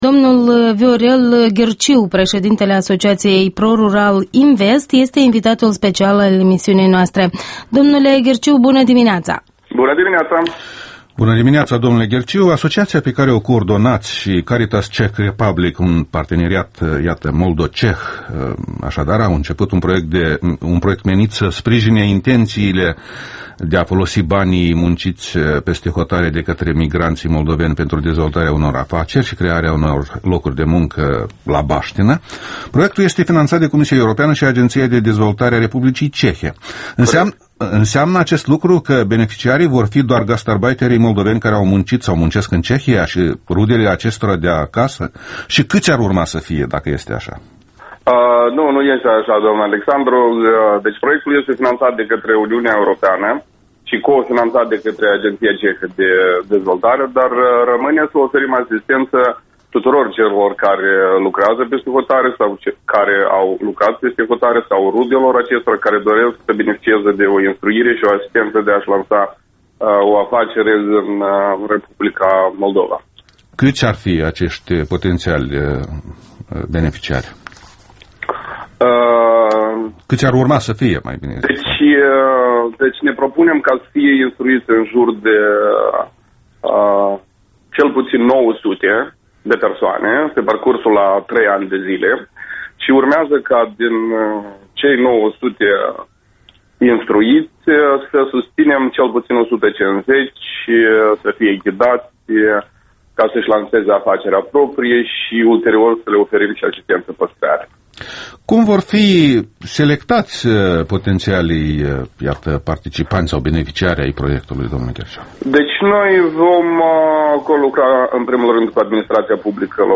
Embed Răspândește Interviul dimineții la Europa Liberă